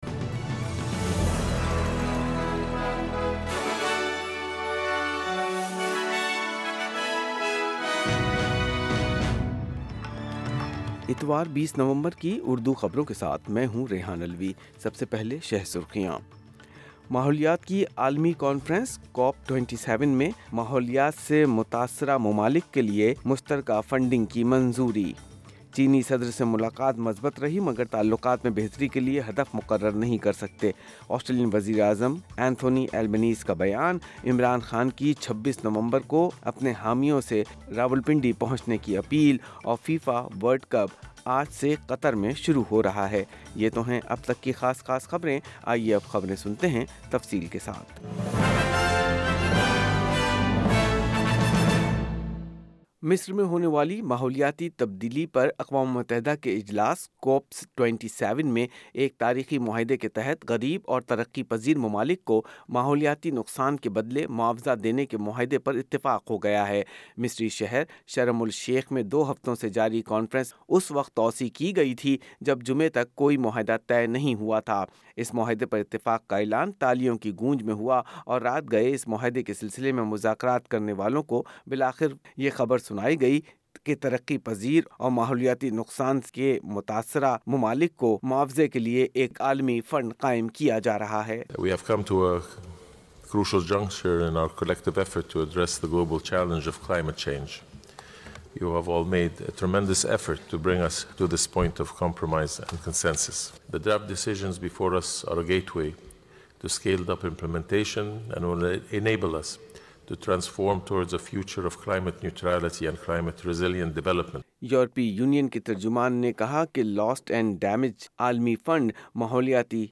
Urdu News 20 November 2022